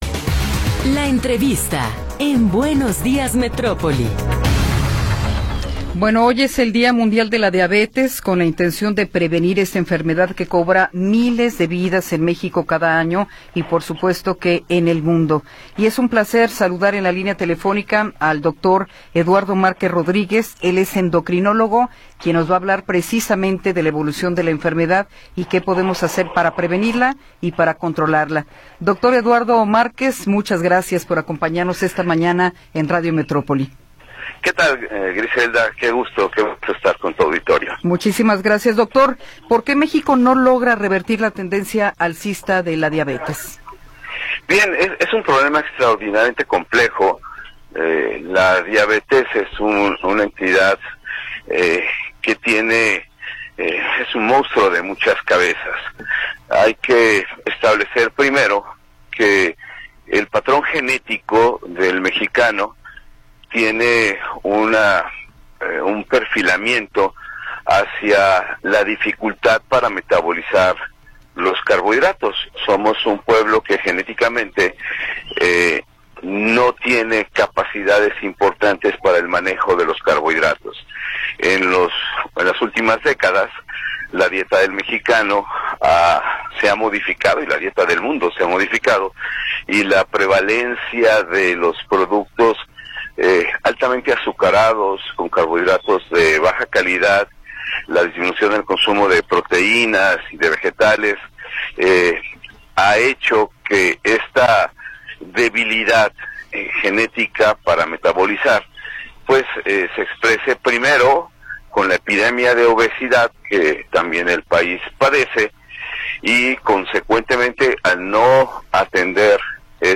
Entrevistas
entrevista-7.m4a